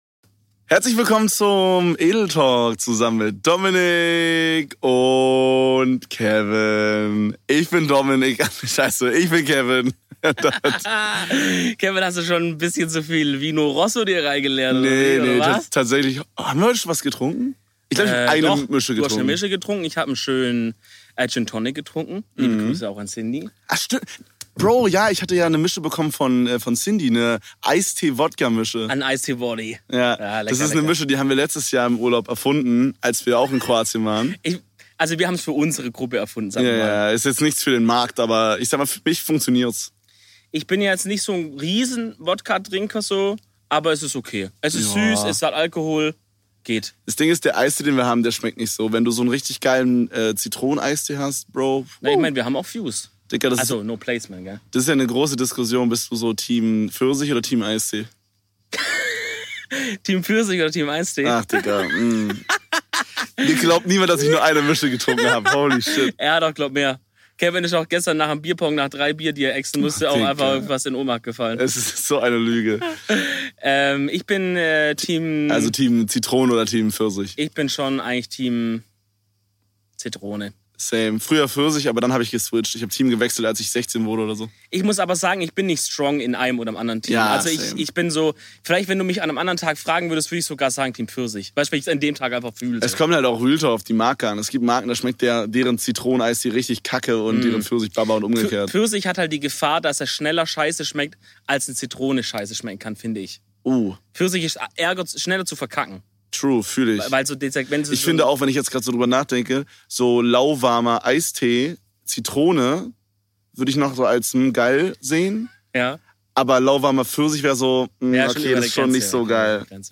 Die zwei Content Götter begrüßen euch diese Folge aus dem Kroatischen Urlaubs Domizil! Und das nicht nur irgendwie, sondern von 3 verschieden Locations um die Villa herum!